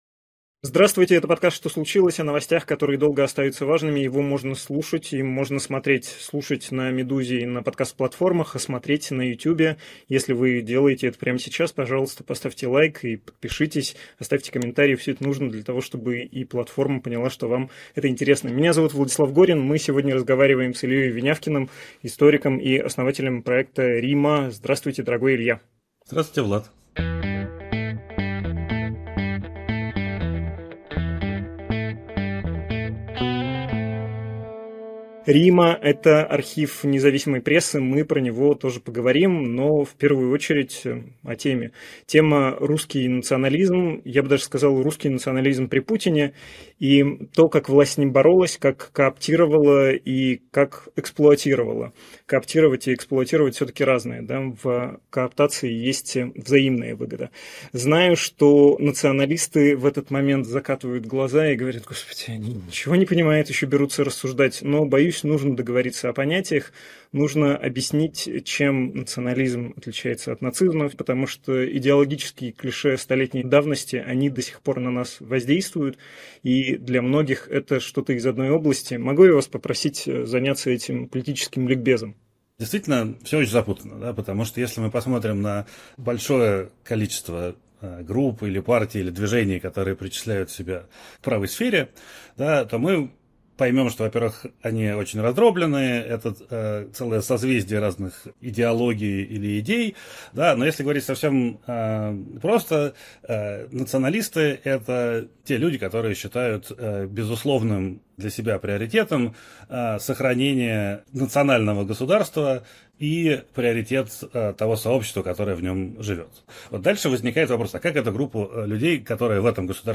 «Что случилось» — новостной подкаст «Медузы».